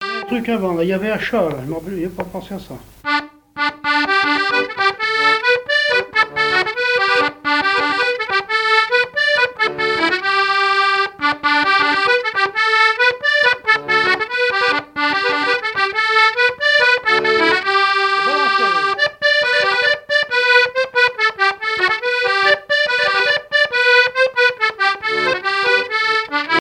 Mémoires et Patrimoines vivants - RaddO est une base de données d'archives iconographiques et sonores.
danse : quadrille : queue du chat
Pièce musicale inédite